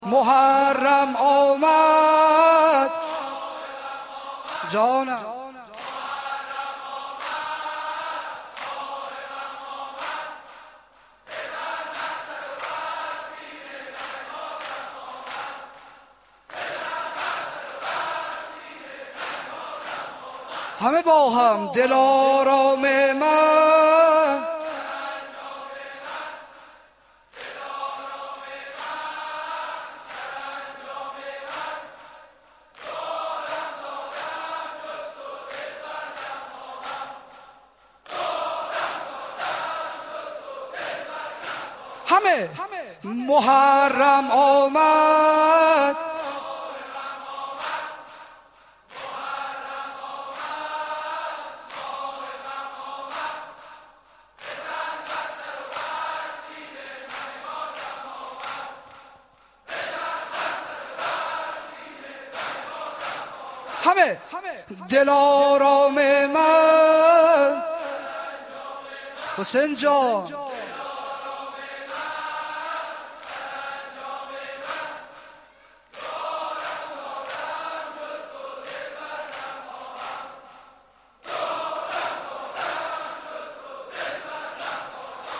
دانلود صوت: نوحه های هیئت نینوای آزادشهر یزد در سال نود وسه